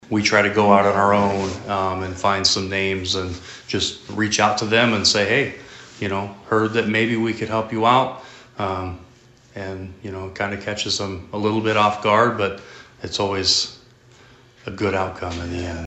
Chief Hogue says “Shop with the Cop” will be held on December 17.